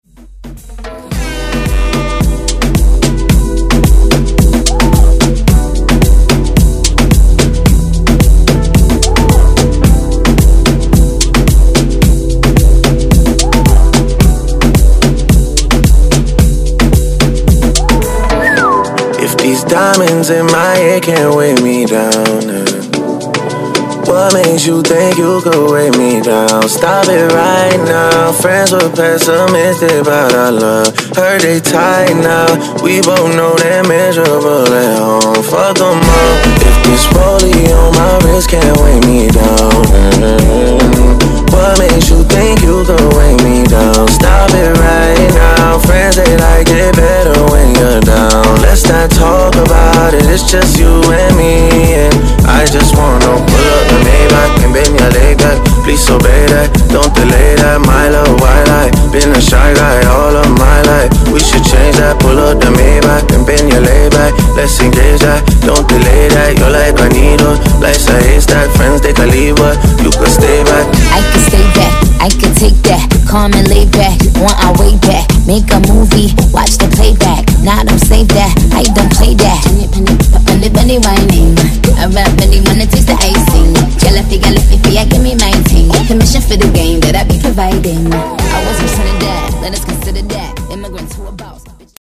Genres: DANCE , MASHUPS , RE-DRUM
Clean BPM: 125 Time